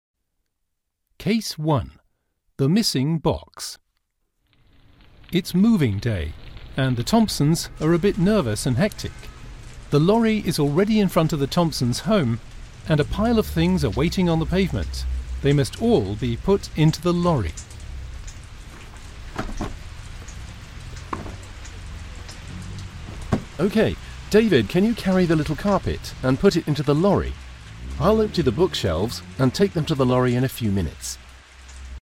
Kriminell gut hören Englisch 6-10 Fesselnde Hörspielkrimis mit differenzierten Aufgaben zur Förderung der Hörkompetenz (6. bis 10.